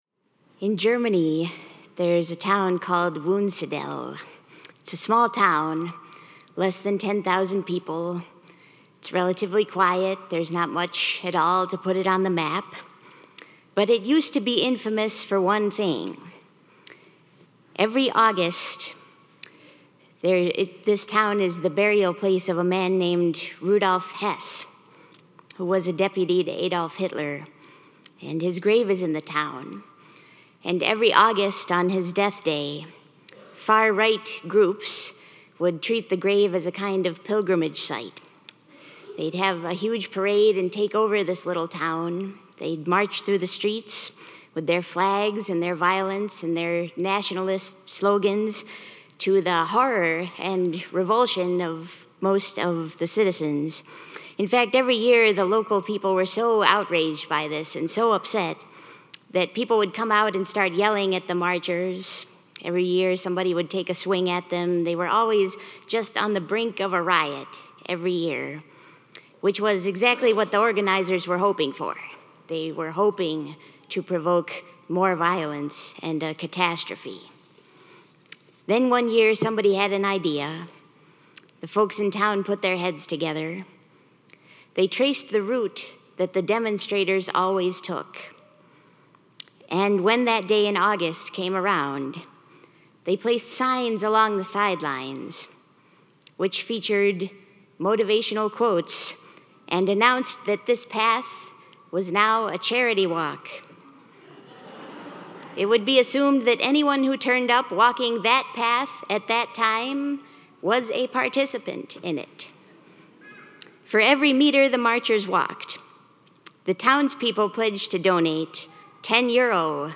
Palm Sunday Liturgy 2022